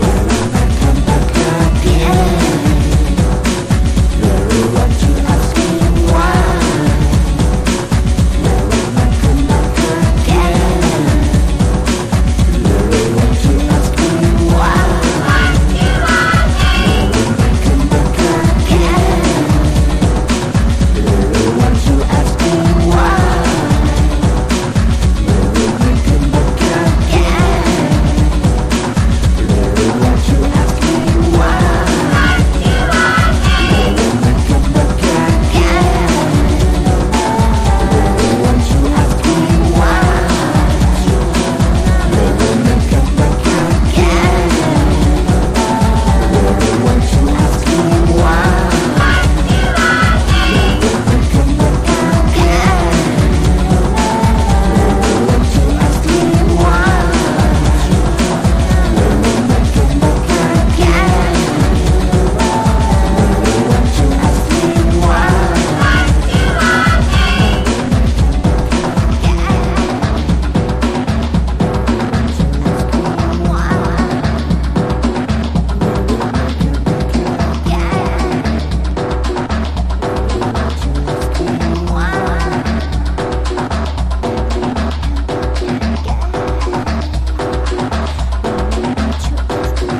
90-20’S ROCK